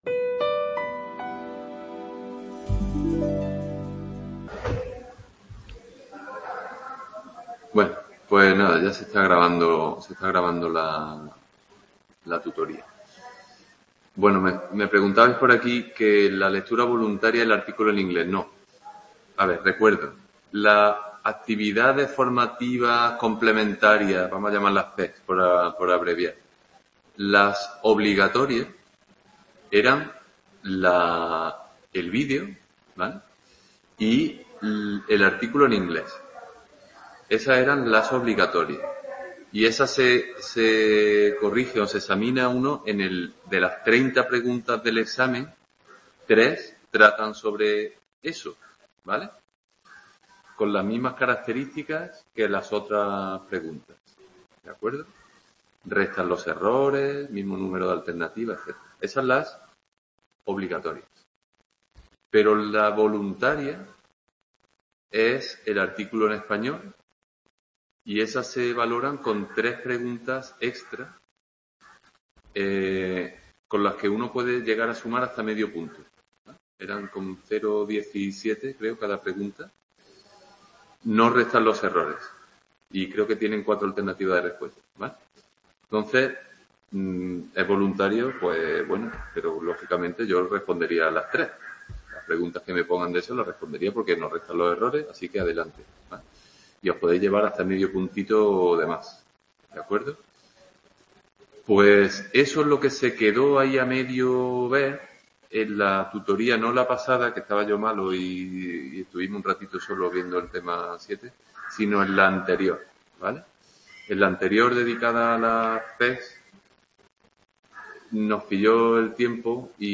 Tutoría Aprendizaje AFC voluntaria curso 2021-22 | Repositorio Digital